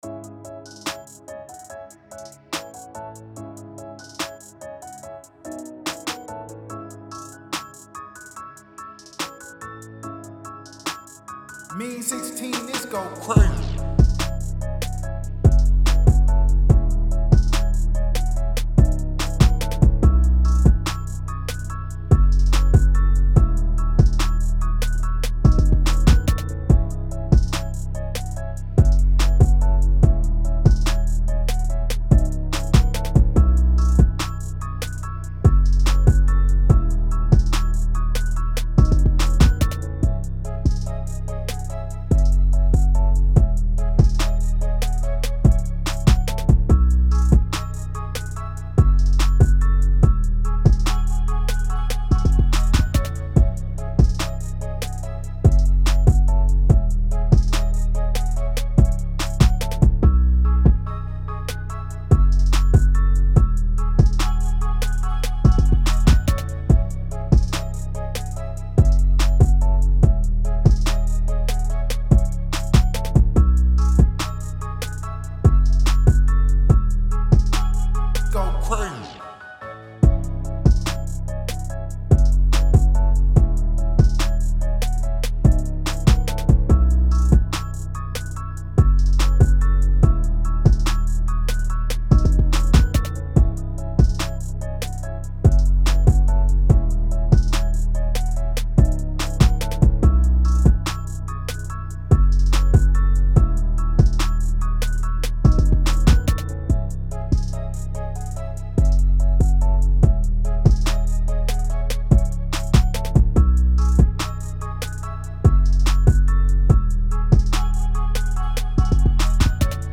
TRAP
C#-Min 144-BPM